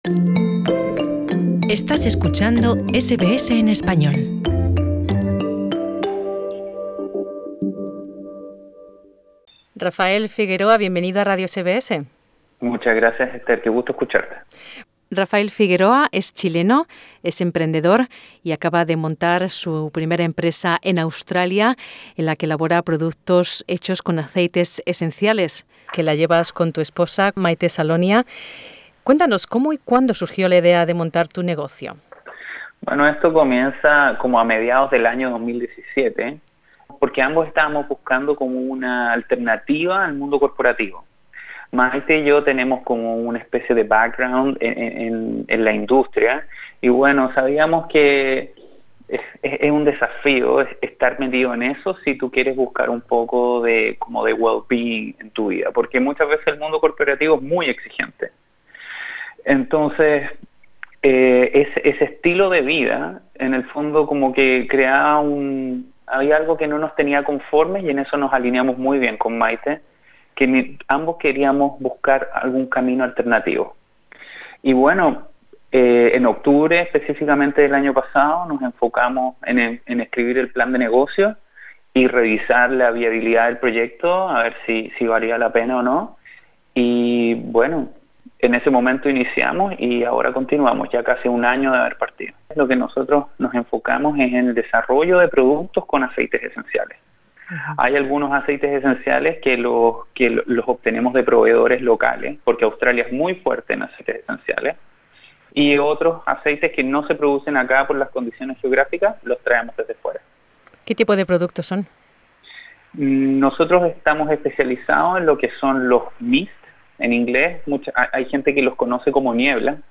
Conoce la experiencia de unos emprendedores latinos que en menos de un año han conseguido montar y gestionar un negocio próspero. Escucha la entrevista.